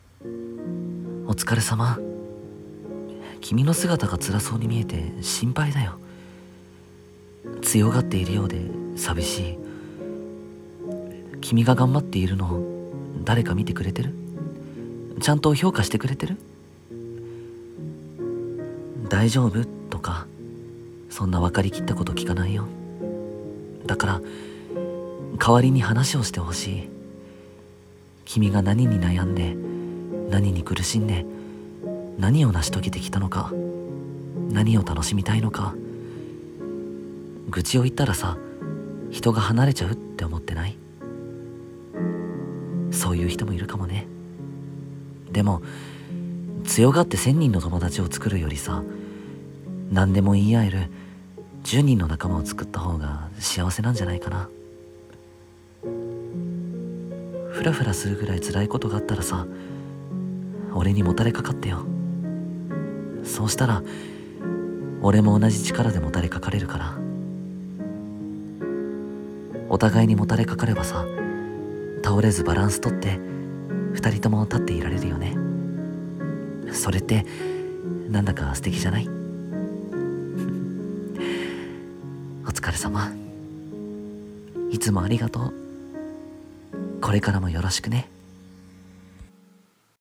1人声劇 お疲れ様、ありがとう